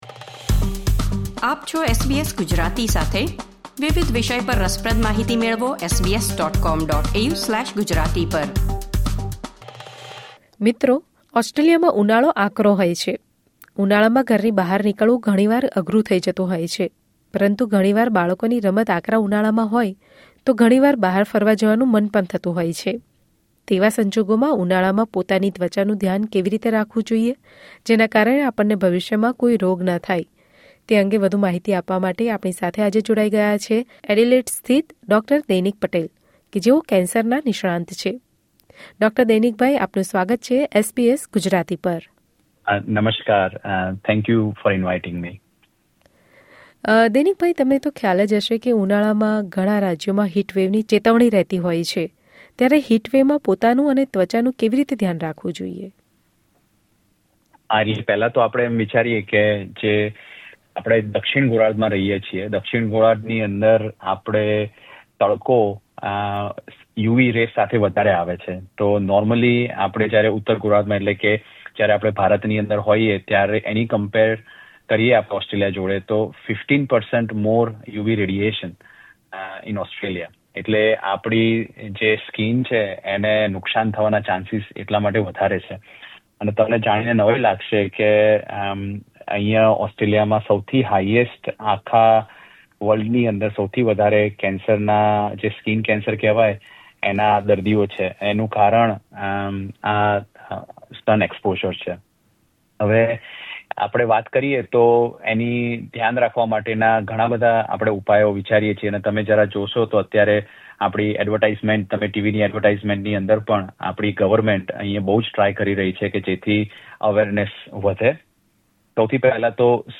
SBS Gujarati સાથેની વાતચીતમાં